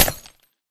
Sound / Minecraft / random / glass3